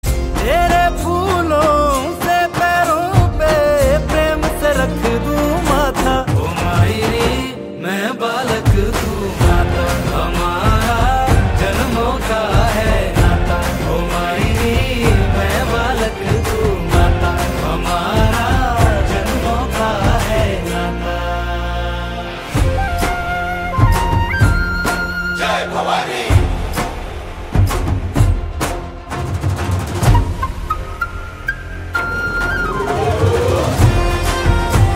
Bhakti Ringtones Devotional Ringtones